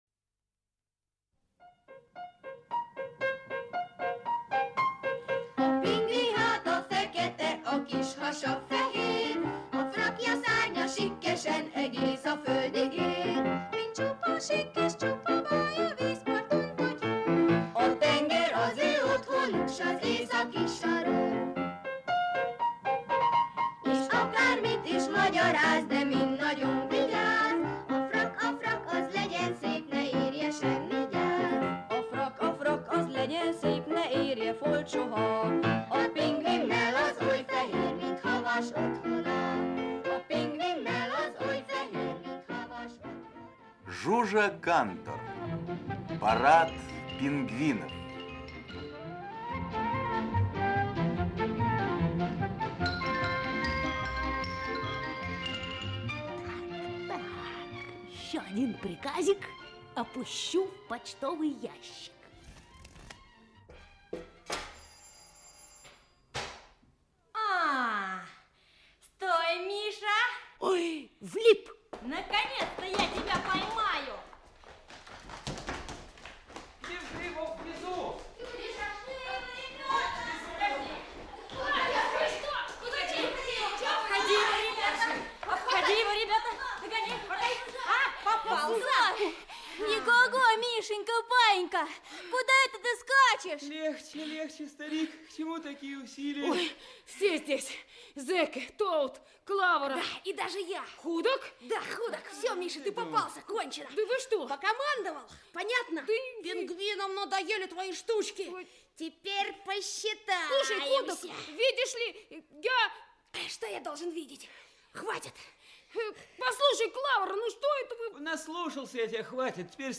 Аудиокнига Парад "пингвинов" | Библиотека аудиокниг